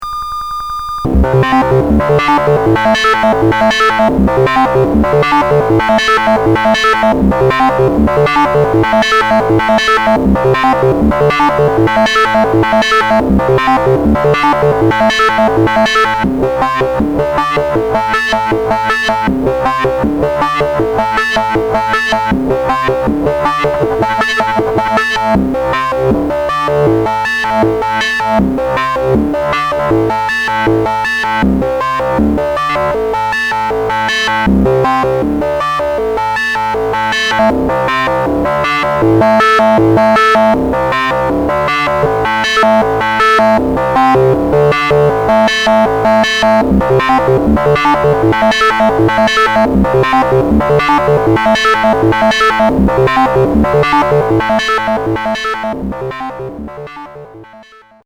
sample five: sequence on the input. output fed into fader module with lfo control.